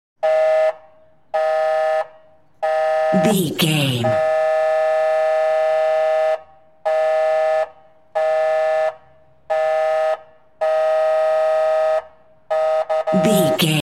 Ambulance Ext Horn
Sound Effects
urban
chaotic
emergency